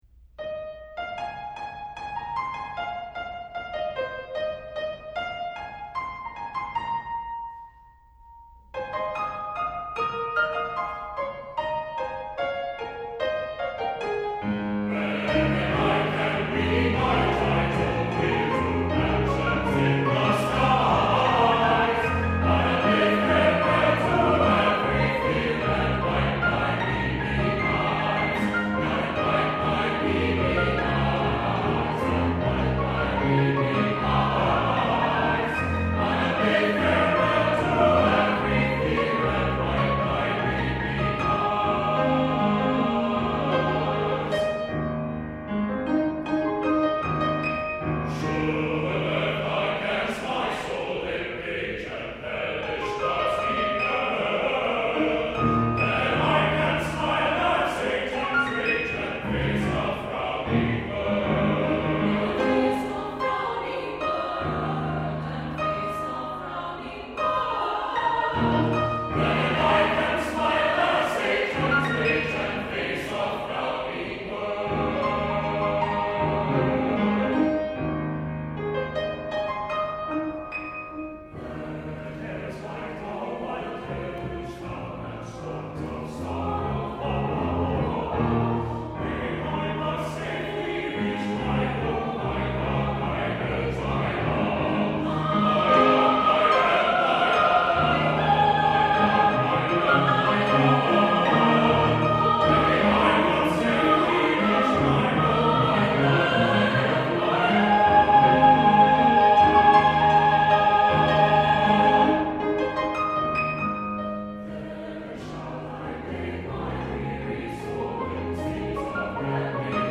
Voicing: SATB and 4 Hand Piano